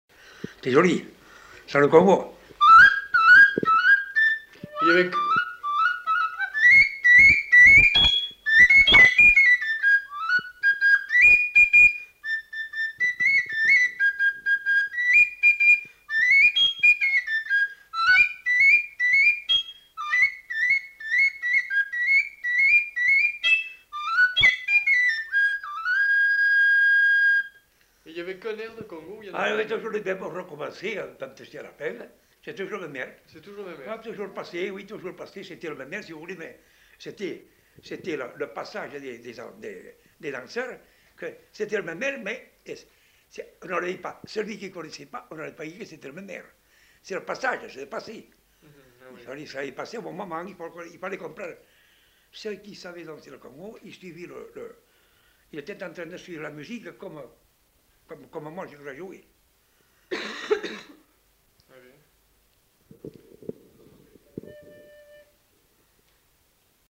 Aire culturelle : Bazadais
Département : Gironde
Genre : morceau instrumental
Instrument de musique : flûte à trois trous
Danse : congo